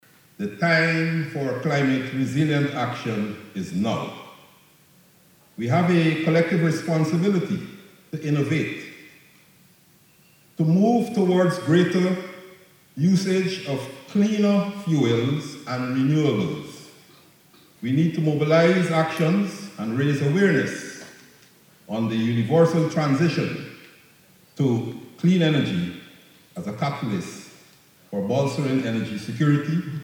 During the Guyana Energy Conference, Prime Minister Brigadier Mark Phillips highlighted the benefits of the Gas to Energy (GTE) project, emphasizing its role in reducing electricity costs, enhancing energy security, and fostering clean energy development in Guyana.